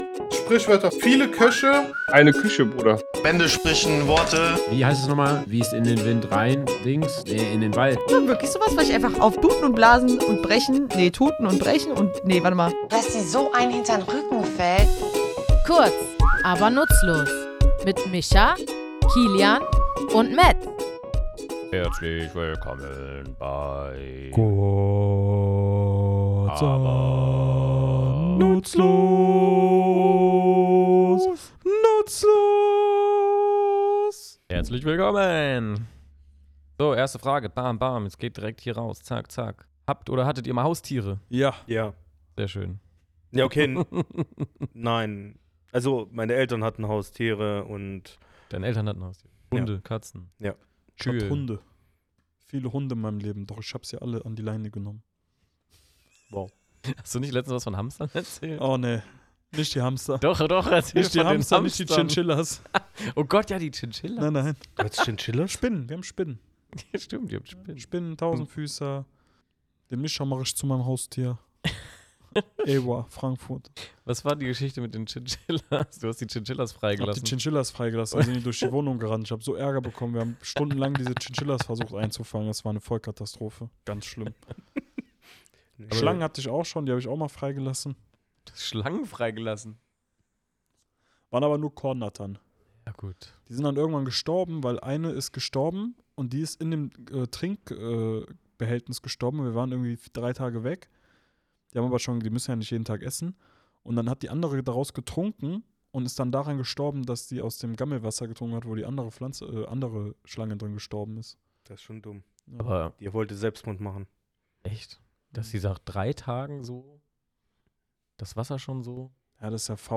Was bedeutet es, wenn ein Geheimnis gelüftet oder etwas verraten wird – und warum spielt dabei ausgerechnet eine Katze im Sack die Hauptrolle? Wir, drei tätowierende Sprachliebhaber, graben in unserem Tattoostudio in der Herkunft dieser Redensart, die bis ins Mittelalter zurückreicht, und erklären, warum hinter dem Sack nicht immer das steckt, was man erwartet.